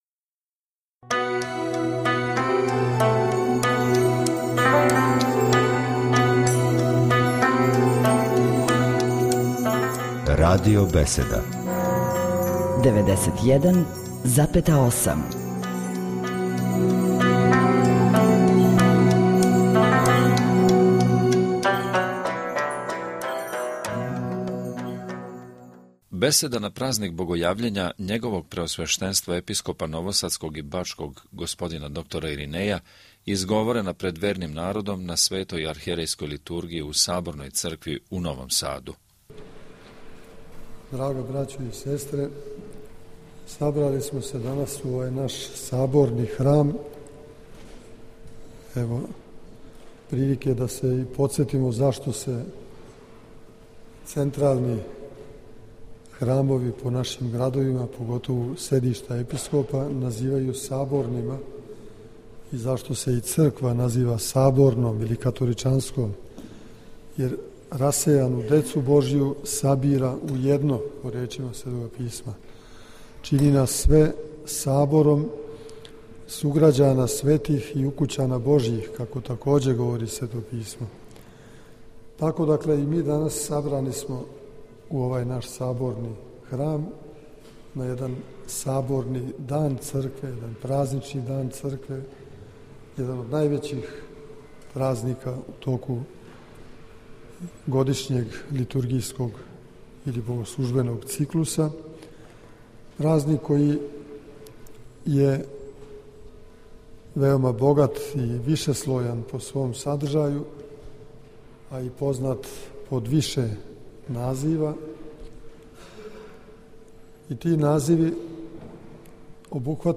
Нови Сад - На празник Богојављења, 6/19. јануара 2011. године, Његово Преосвештенство Епископ бачки Господин Иринеј служио је свету архијерејску Литургију у новосадском Саборном храму и извршио чин великог освећења воде.